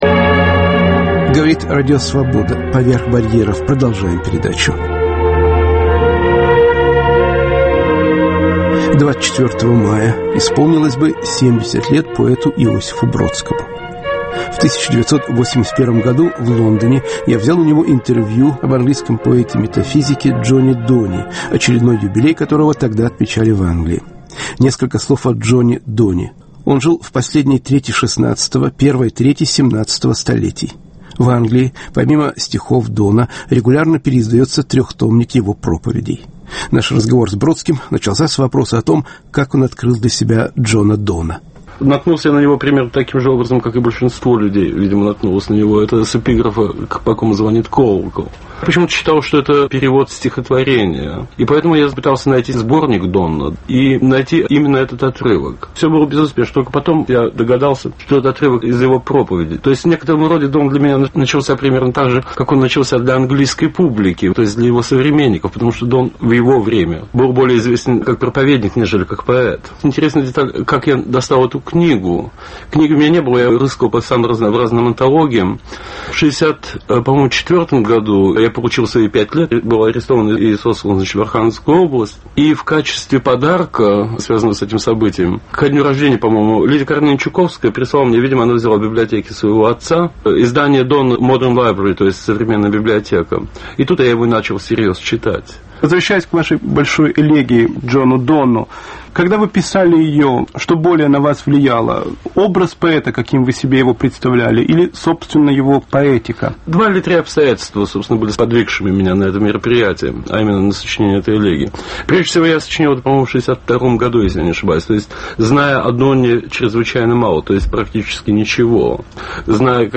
Радиоинтервью 1981 года.